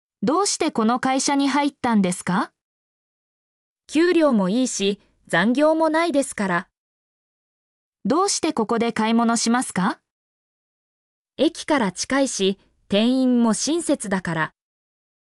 mp3-output-ttsfreedotcom-43_oI2X9dDq.mp3